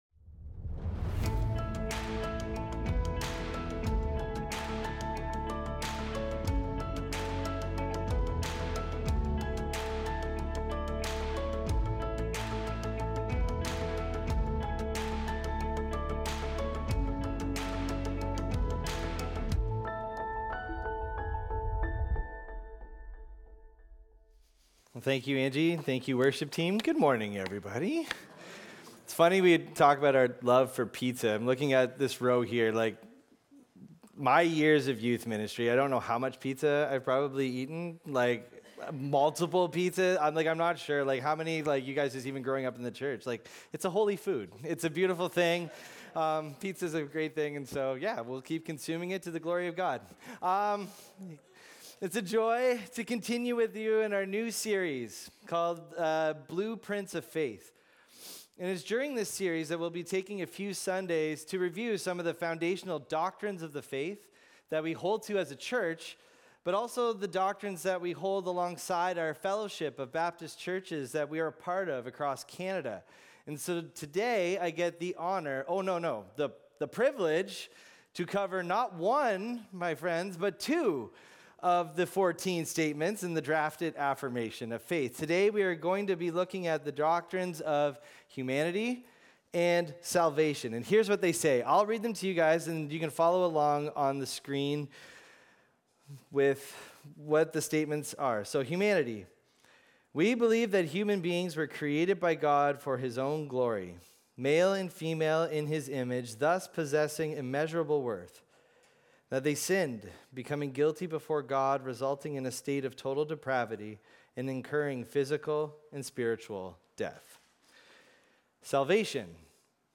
Recorded Sunday, October 5, 2025, at Trentside Bobcaygeon.